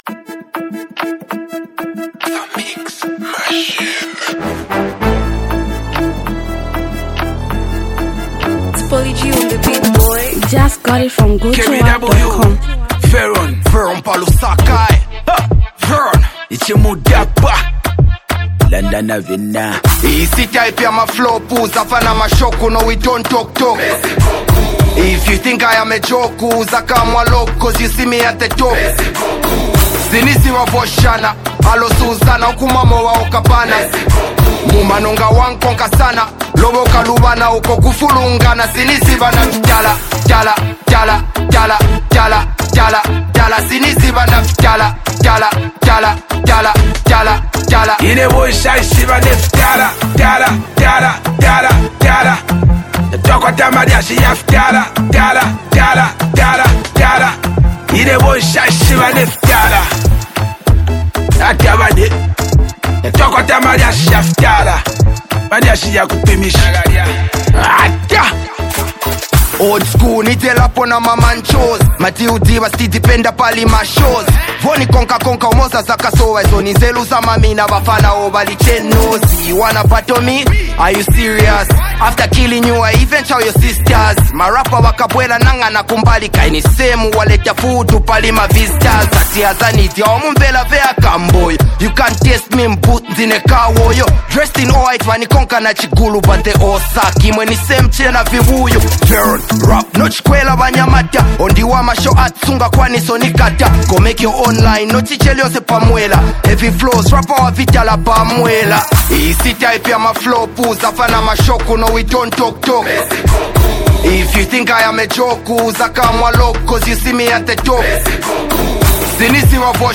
Zambian Mp3 Music
buzzing street anthem